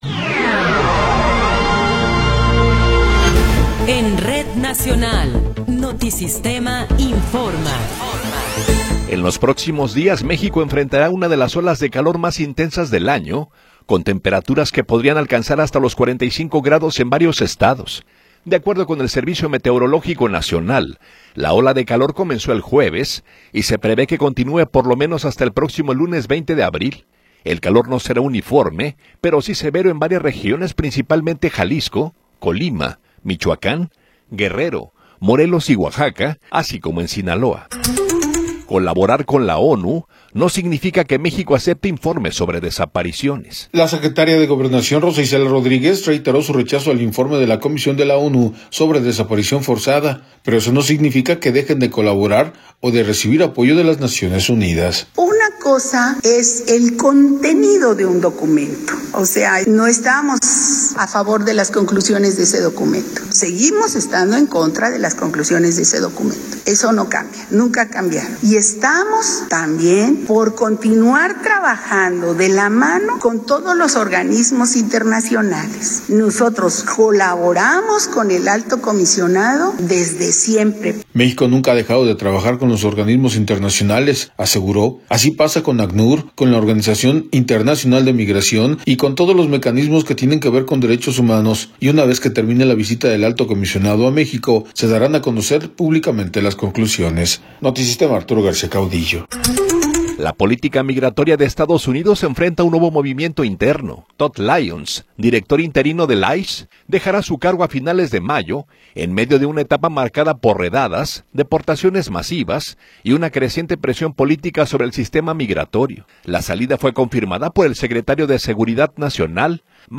Noticiero 10 hrs. – 17 de Abril de 2026
Resumen informativo Notisistema, la mejor y más completa información cada hora en la hora.